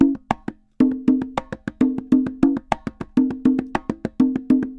Congas_Samba 100_6.wav